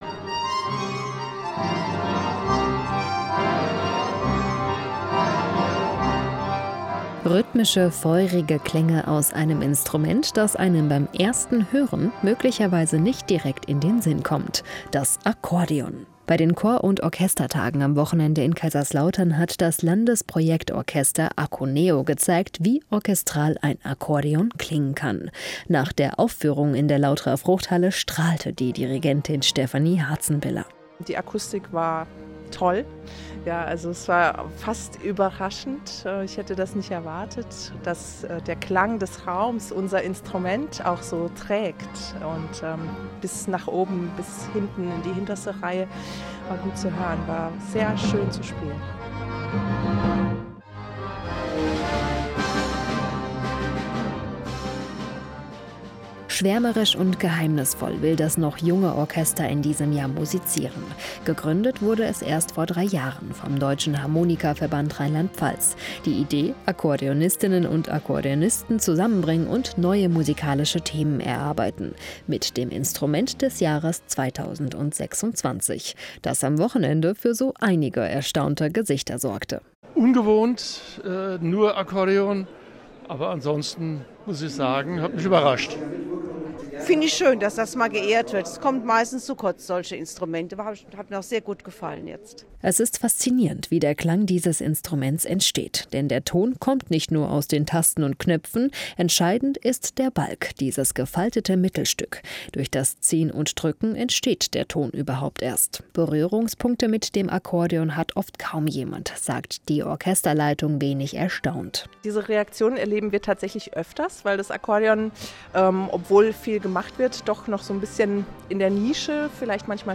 Die Tage der Chor- und Orchestermusik sind eines der wichtigsten Treffen der Amateurmusik in Deutschland. Jedes Jahr richtet der Bundesmusikverband Chor & Orchester das Festival in einer anderen Stadt aus, diesmal – passend zum 750-jährigen Stadtjubiläum – in Kaiserslautern.